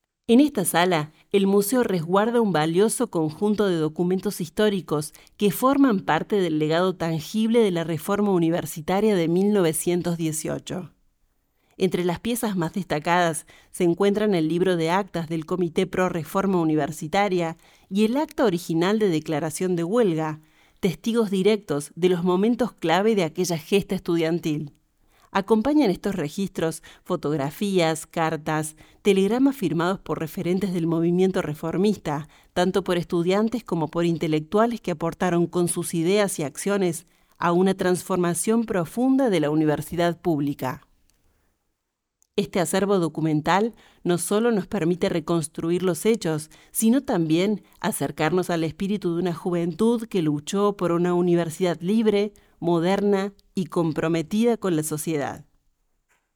[Audioguía]